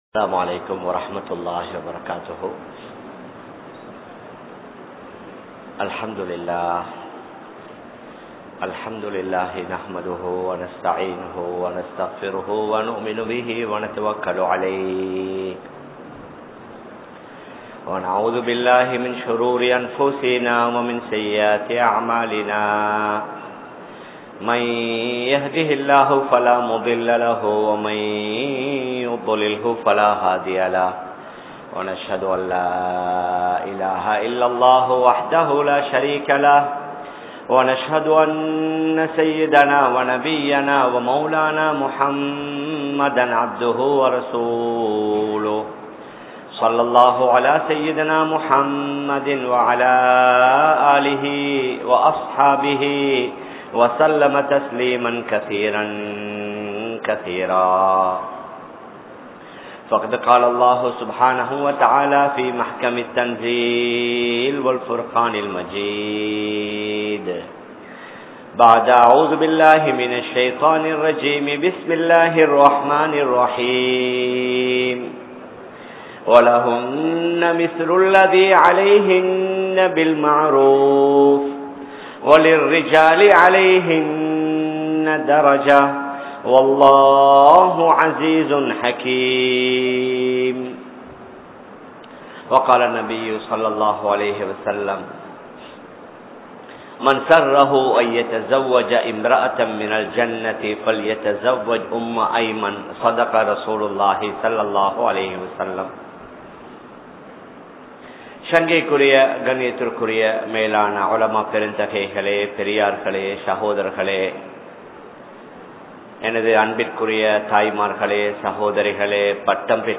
Islaamiya Pengal (இஸ்லாமிய பெண்கள்) | Audio Bayans | All Ceylon Muslim Youth Community | Addalaichenai